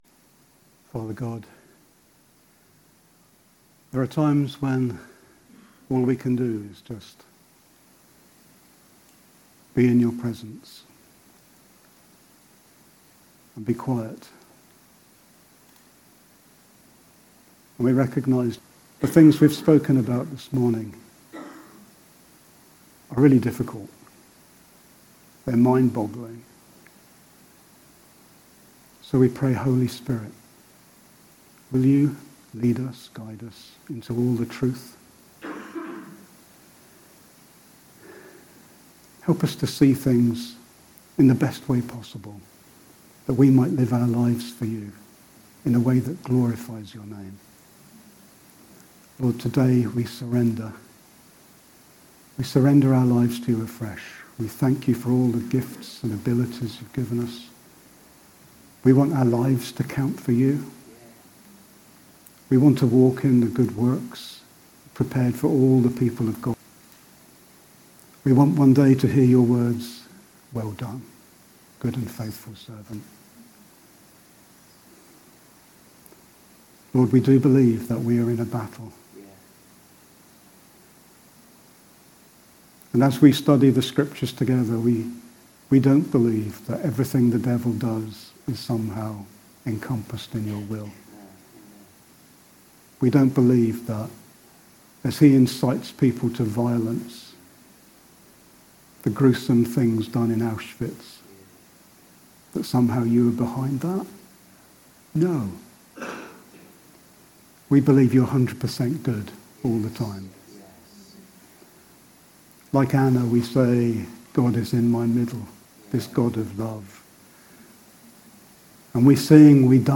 7-July-2019-prayer.mp3